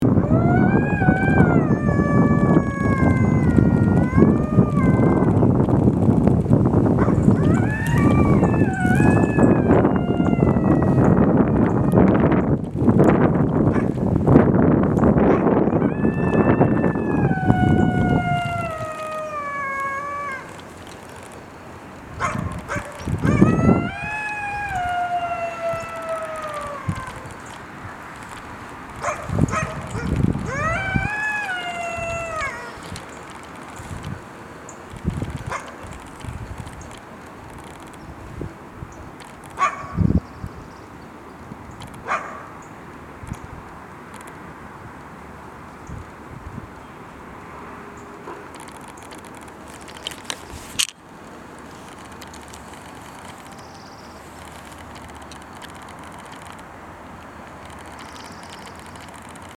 Lugubrious Howl After Picking Up An Unwelcome Scent
Kicking dirt after his howl (with a youngster beside him)
This lugubrious howl capped extensive and intense sniffing by the resident alpha male of his territorial area.
Immediately following the howl, he “kicked” the ground: he was clearly angry, but whomever he was angry at was not present.
9-1-monte-lugubrious-howl-kicking.mp3